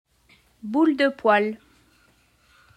How to pronounce Boule de Poil